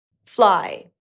How-to-pronounce-FLY-in-American-English_cut_1sec.mp3